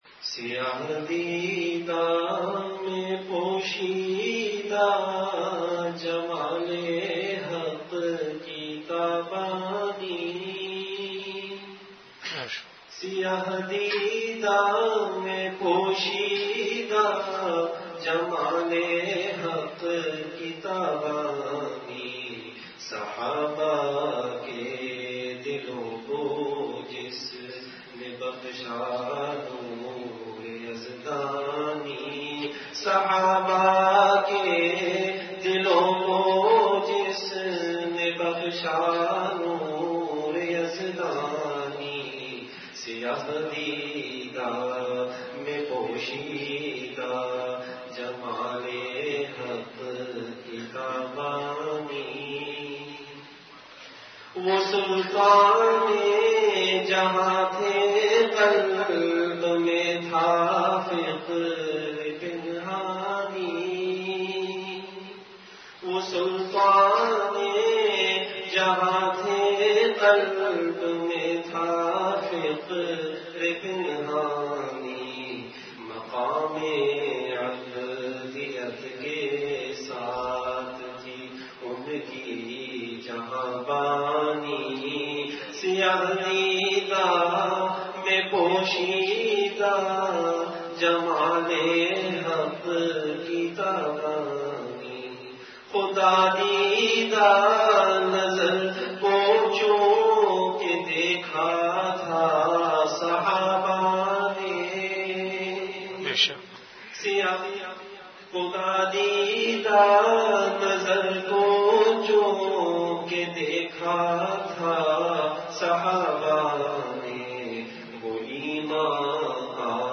Majlis-e-Zikr · Home Sahaba